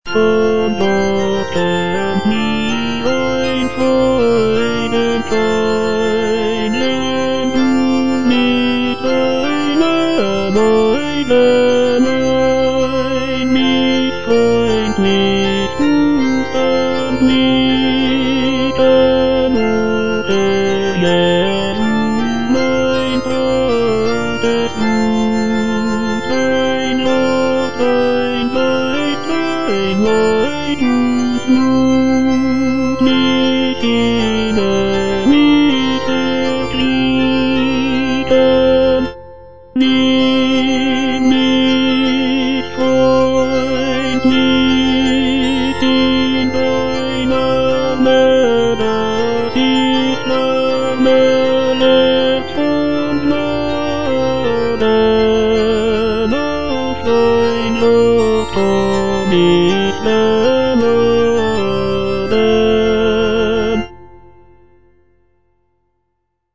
J.S. BACH - CANTATA "ERSCHALLET, IHR LIEDER" BWV172 (EDITION 2) Von Gott kömmt mir ein Freudenschein - Tenor (Voice with metronome) Ads stop: auto-stop
The text celebrates the coming of the Holy Spirit and the birth of the Christian Church. The music is characterized by its lively rhythms, rich harmonies, and intricate counterpoint.